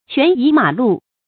權移馬鹿 注音： ㄑㄨㄢˊ ㄧˊ ㄇㄚˇ ㄌㄨˋ 讀音讀法： 意思解釋： 語本秦趙高指鹿為馬事。